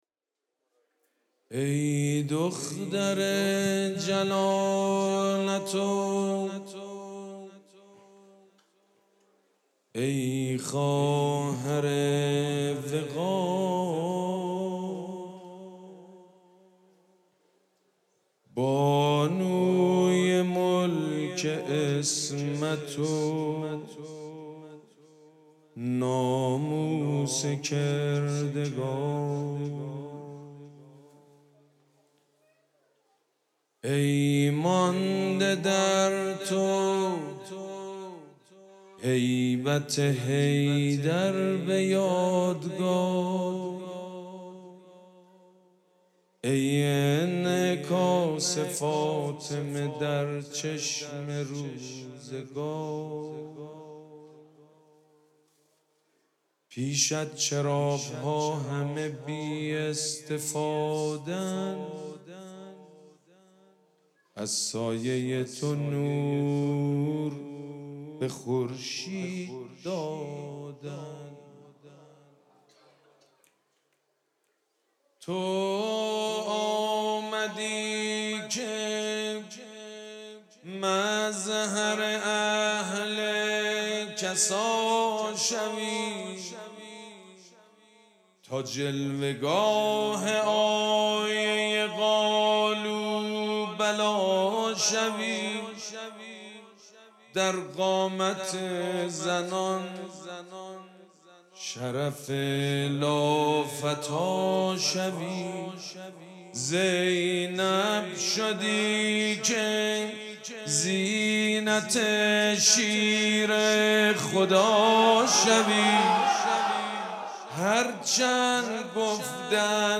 مراسم جشن ولادت حضرت زینب سلام‌الله‌علیها
حسینیه ریحانه الحسین سلام الله علیها
مدح
حاج سید مجید بنی فاطمه